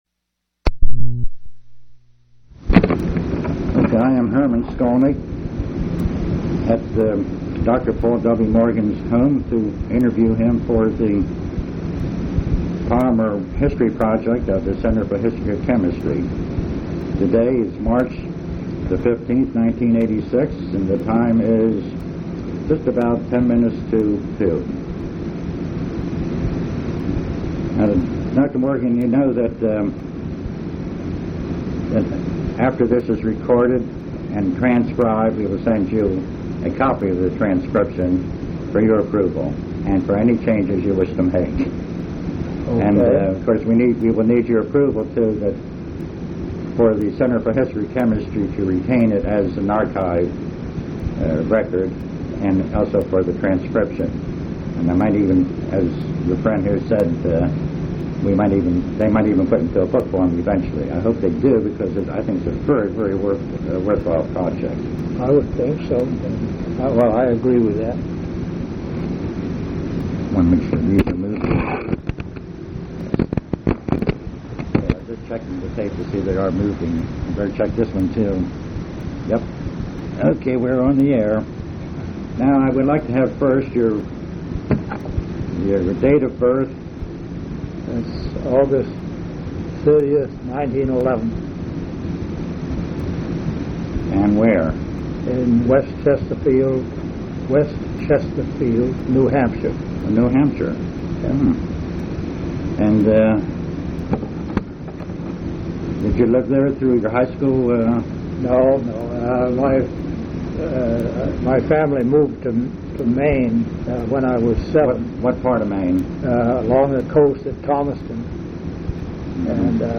Oral histories
Place of interview Pennsylvania--West Chester